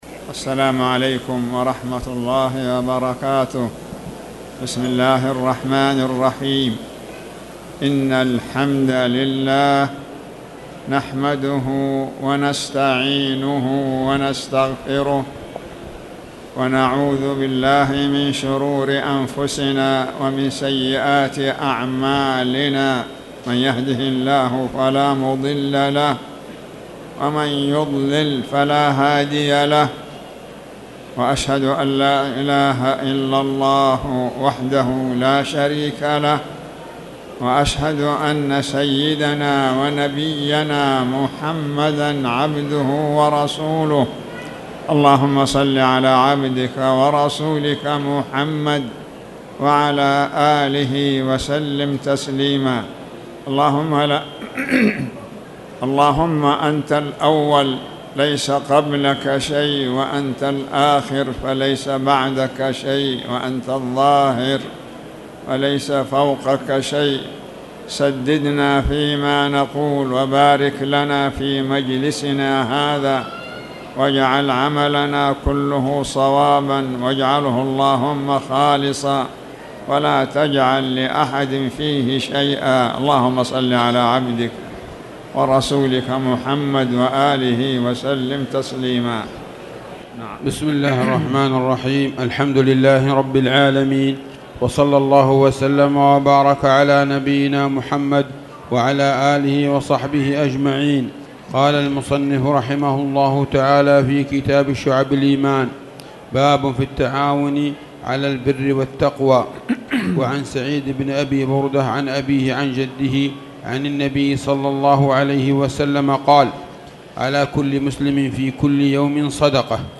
تاريخ النشر ٢٠ شعبان ١٤٣٨ هـ المكان: المسجد الحرام الشيخ